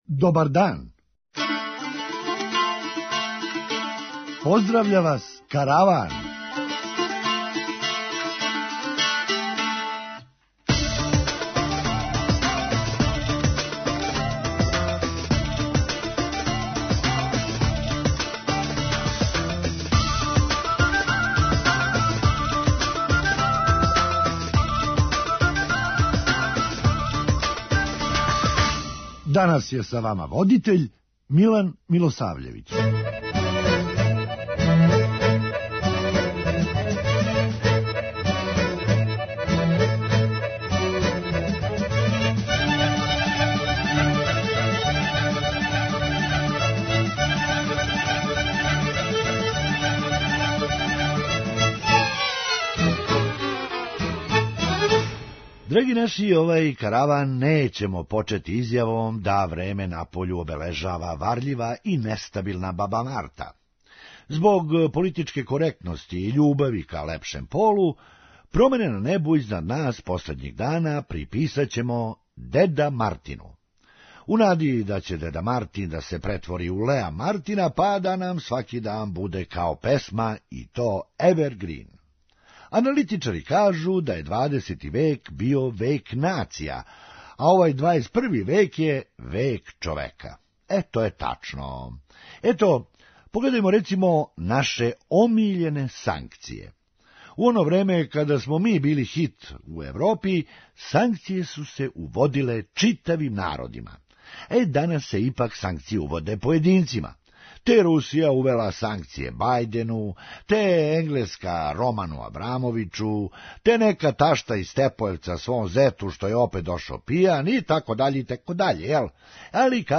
Хумористичка емисија
Друга половина није учествовала у анкети јер је већ у Украјини. преузми : 8.97 MB Караван Autor: Забавна редакција Радио Бeограда 1 Караван се креће ка својој дестинацији већ више од 50 година, увек добро натоварен актуелним хумором и изворним народним песмама.